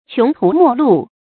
qióng tú mò lù
穷途末路发音
成语正音末，不能读作“wèi”。